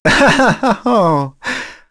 Fluss-Vox_Happy1.wav